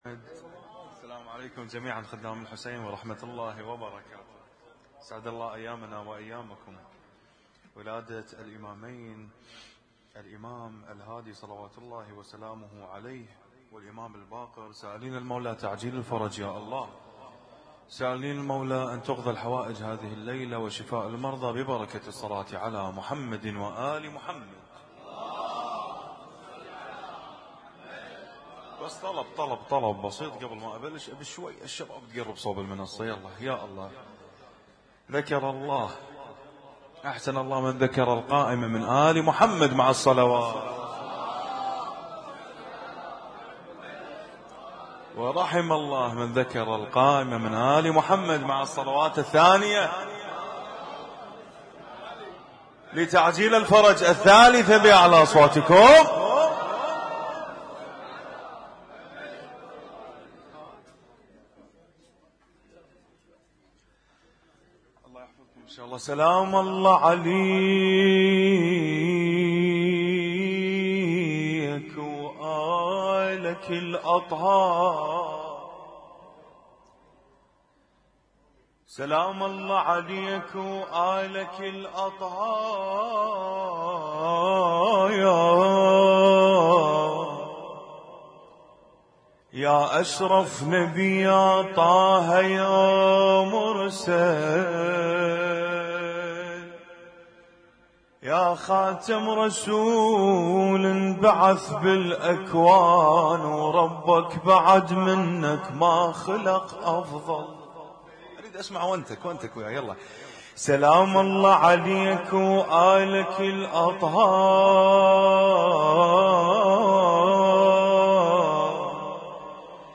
Husainyt Alnoor Rumaithiya Kuwait
القارئ: الرادود
اسم التصنيف: المـكتبة الصــوتيه >> المواليد >> المواليد 1441